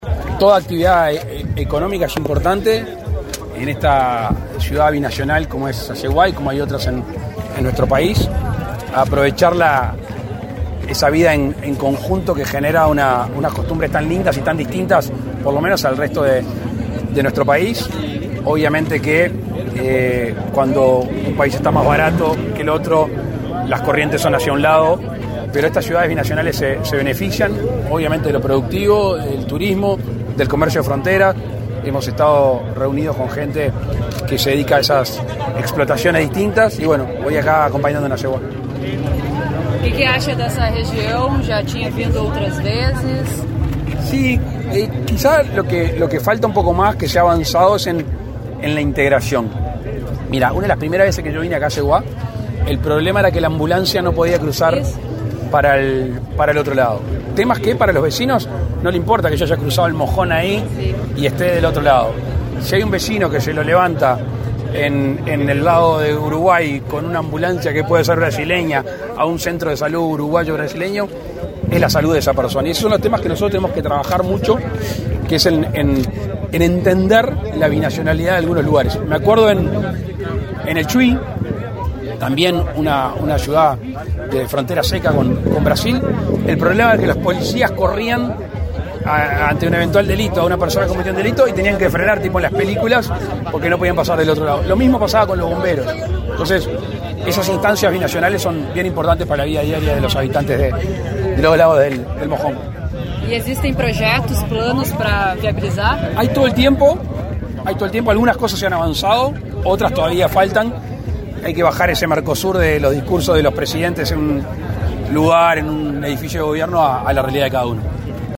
Declaraciones a la prensa de Brasil del presidente de la República, Luis Lacalle Pou
Tras el evento, el mandatario realizó declaraciones a medios de prensa de Brasil.